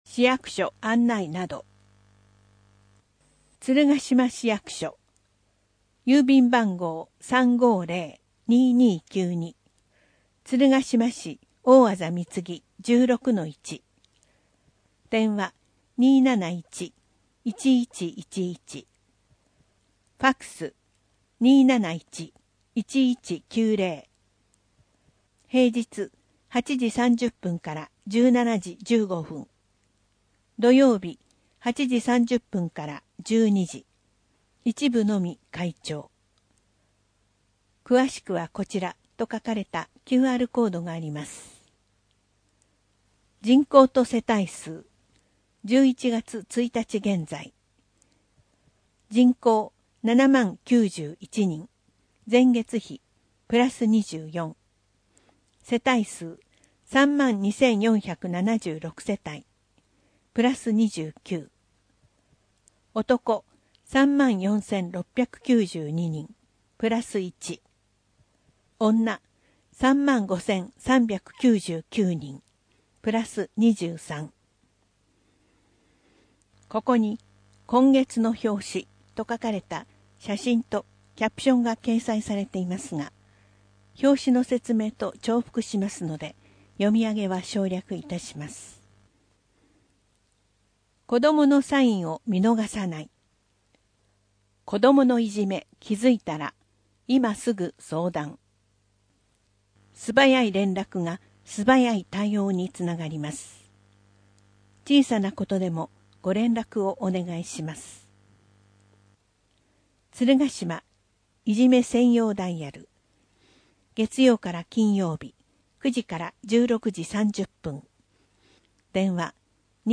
声の広報つるがしまは、「鶴ヶ島音訳ボランティアサークルせせらぎ」の皆さんが「広報つるがしま」の内容を音訳し、「デイジー鶴ヶ島」の皆さんがデイジー版CDを製作して、目の不自由な方々へ配布をしています。